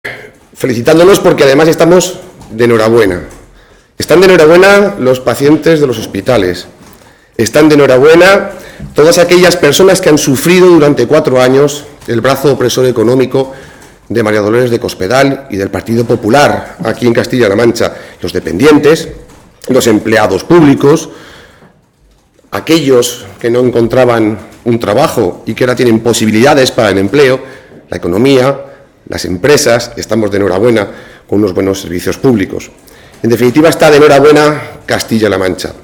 El presidente del Grupo Socialista en las Cortes de Castilla-La Mancha, Rafael Esteban, ha señalado que hoy es un buen día para los ciudadanos de nuestra región porque se han aprobado los primeros presupuestos de Emiliano García-Page, unas cuentas radicalmente diferentes a las que se aprobaban la pasada legislatura “y que van a traer consigo la recuperación económica y social de nuestra comunidad autónoma tras los brutales recortes del gobierno de Cospedal”.
Cortes de audio de la rueda de prensa